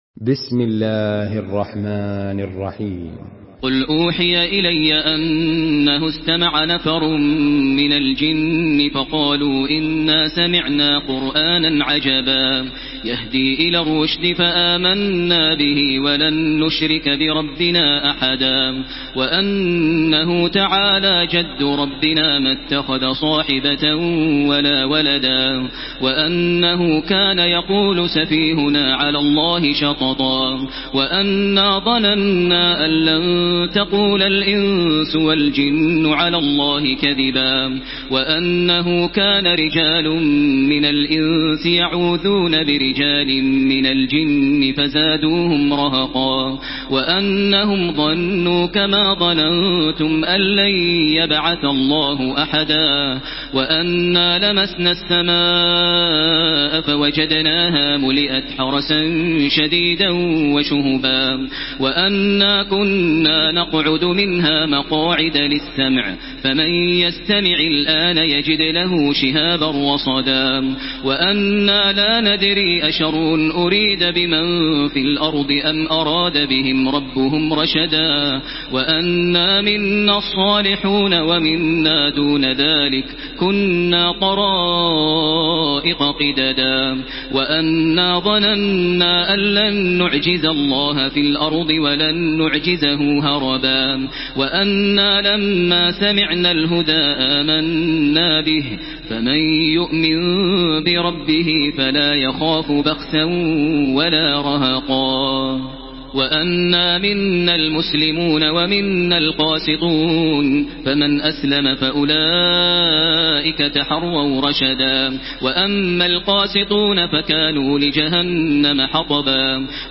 تراويح الحرم المكي 1433
مرتل حفص عن عاصم